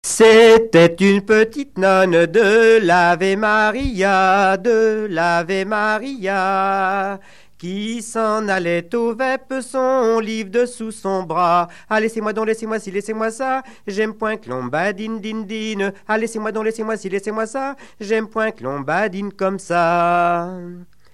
Genre laisse
Enquête Tap Dou Païe
Catégorie Pièce musicale inédite